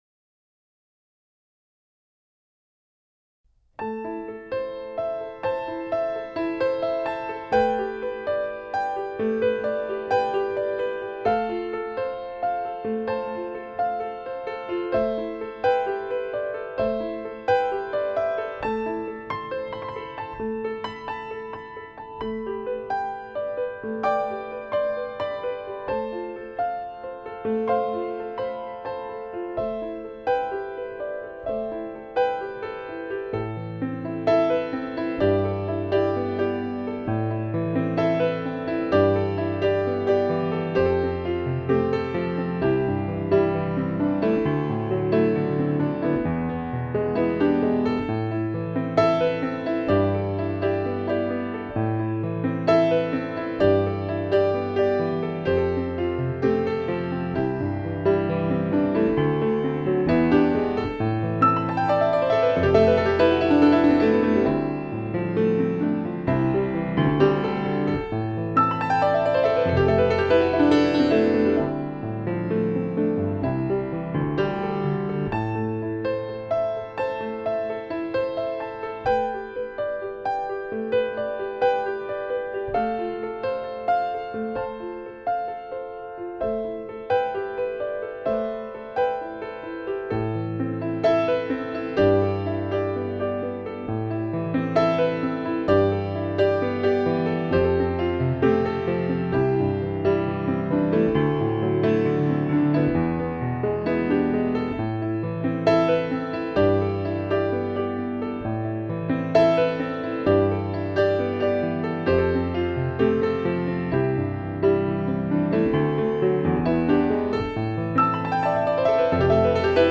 original piano music with a romantic touch